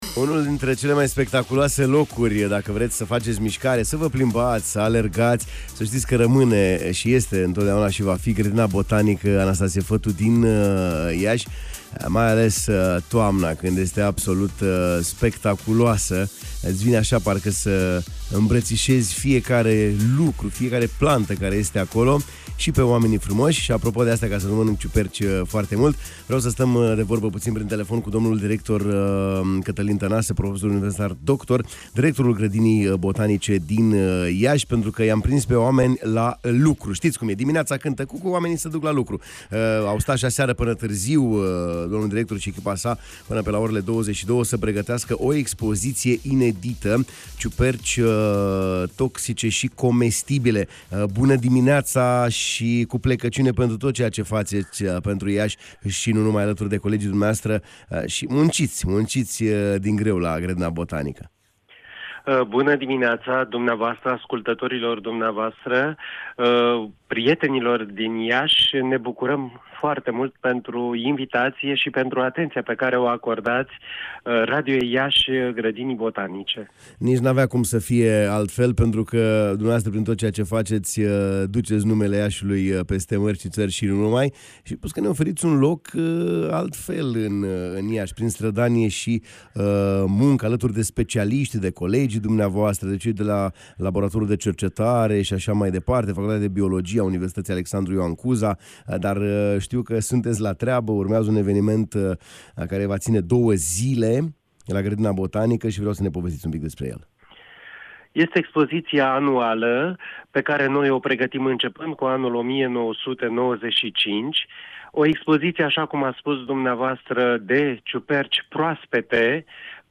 în direct la Radio Iași